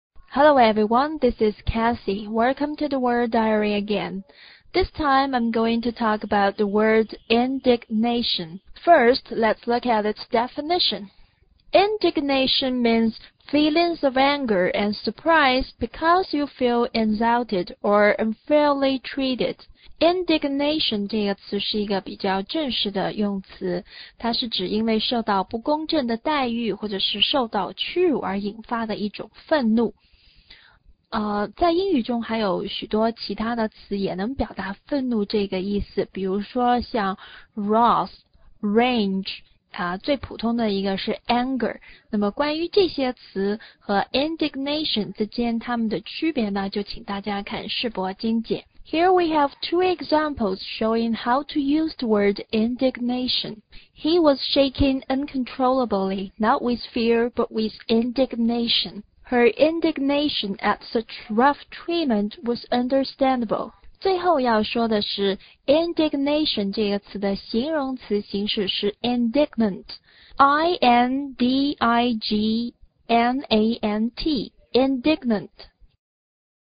indignation的重音在”na”上，”a”发字母“a”的音。